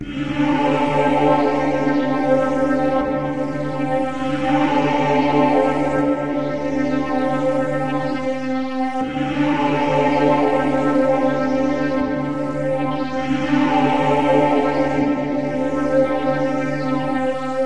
描述：一个半宗教的声音，有一个现代的小合唱团。用Ableton.
标签： 宗教 合成器 声音
声道立体声